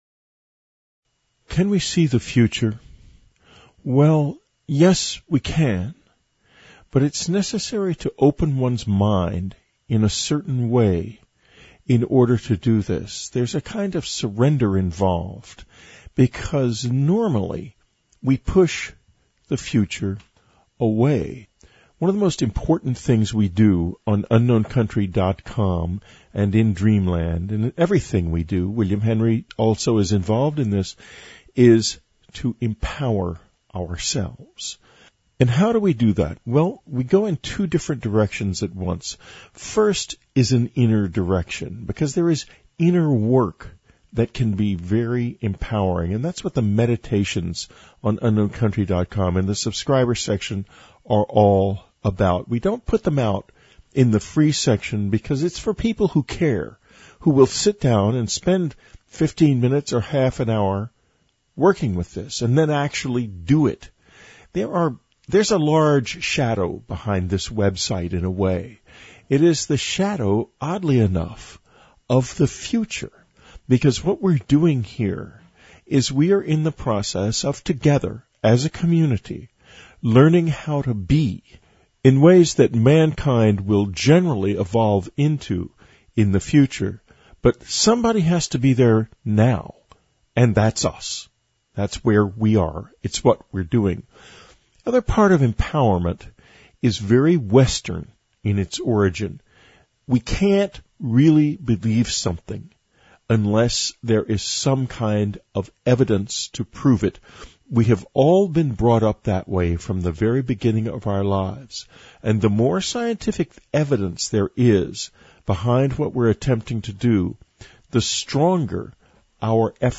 Meditation: Looking Into the Future
Whitley Strieber not only says yes you can, here he tells us just how he does it, what limitations he finds, and how to tell the difference between your imagination and your second sight. And then he guides us in a beautiful meditation that reminds us of the true power of our own minds.